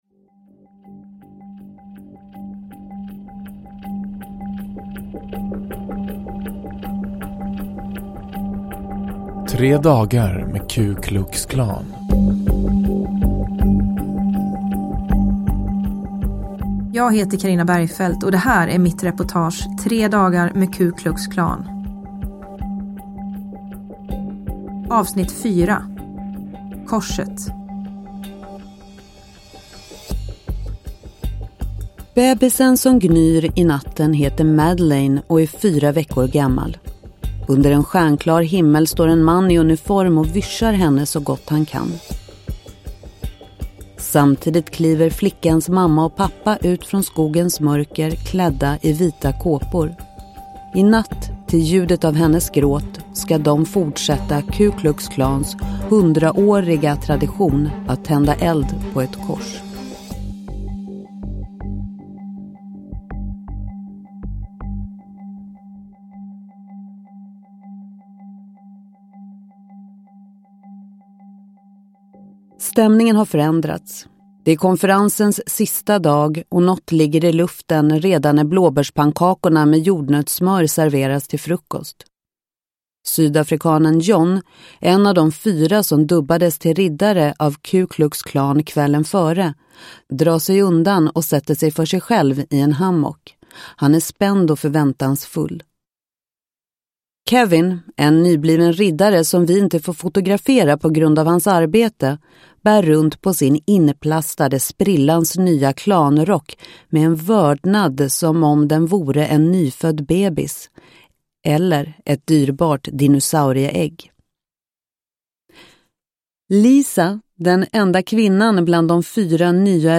Bergfeldts Amerika. S2A4, Tre dagar med Ku Klux Klan – Ljudbok – Laddas ner